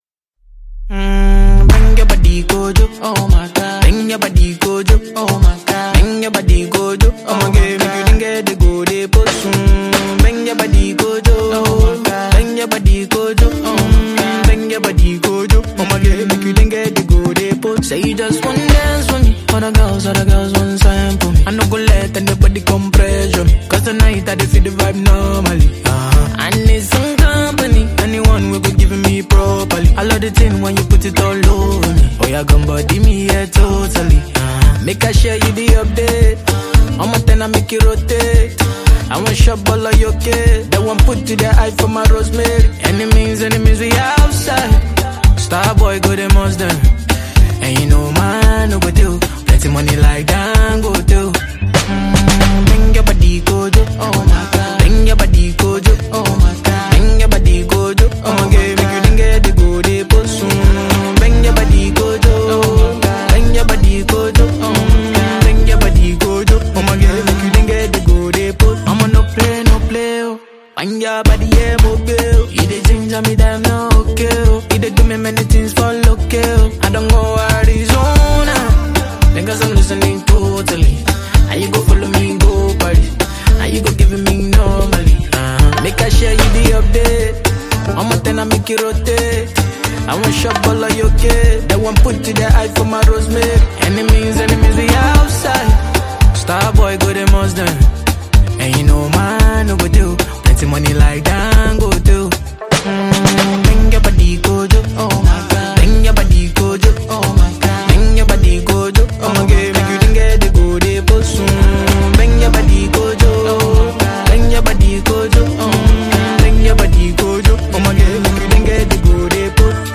With this fresh, seductive offering